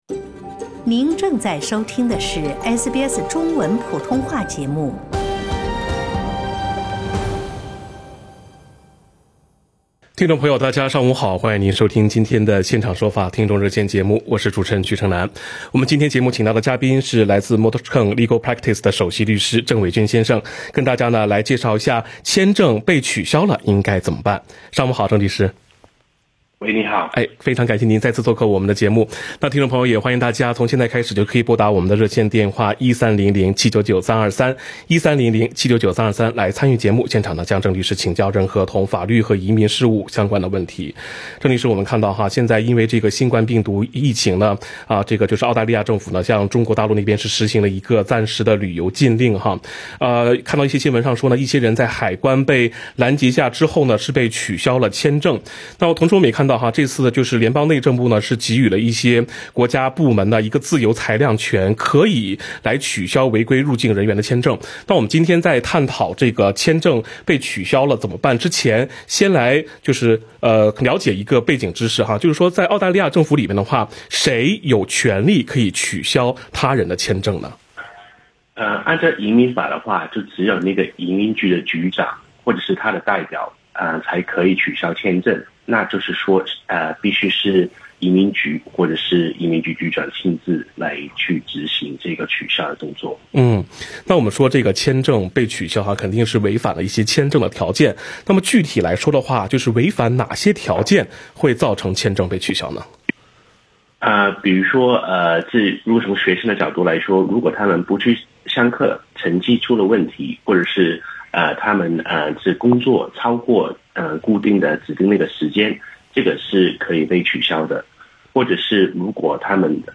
《现场说法》听众热线逢每周二上午8点30分至9点播出。